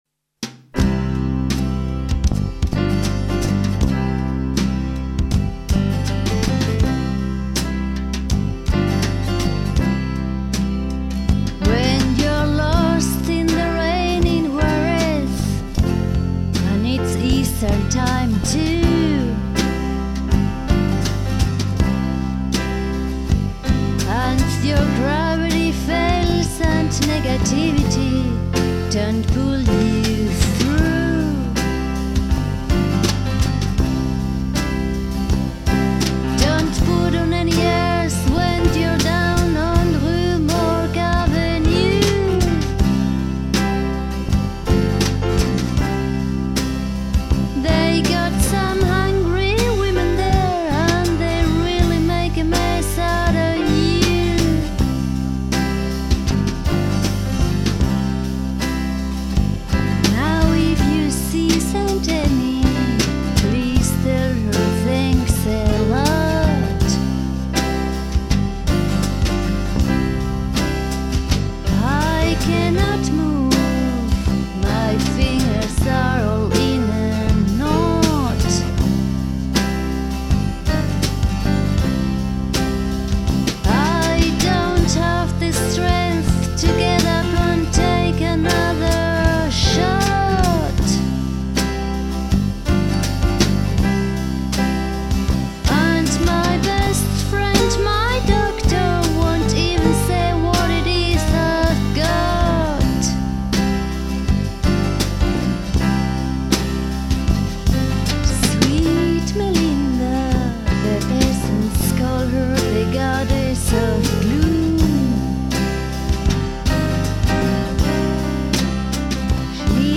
bass
drums, percussion
guitars, keyboards, autoharp, vocal